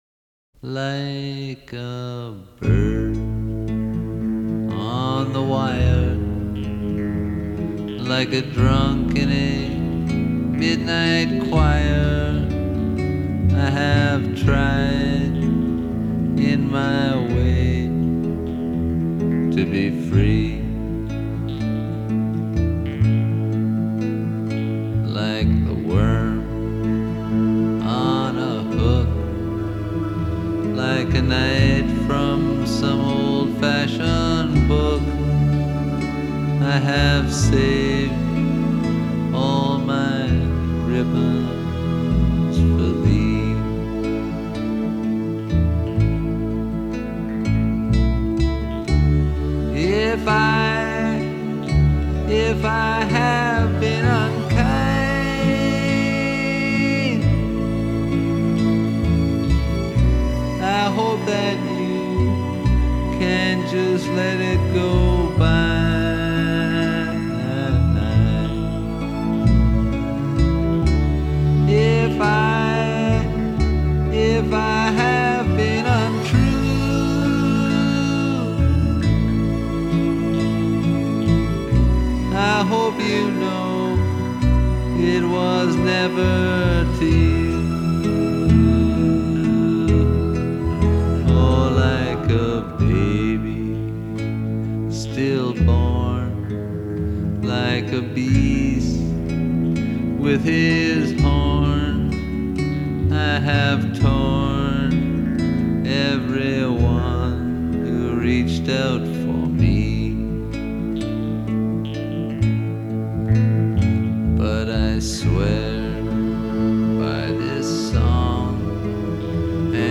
Folk, Singer-Songwriter